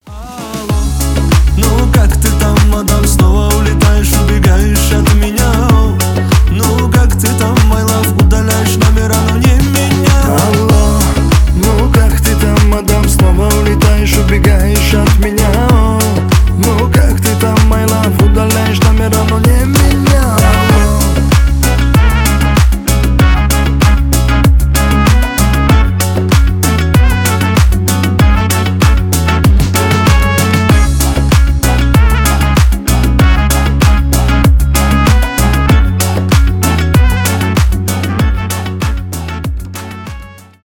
поп
кавказские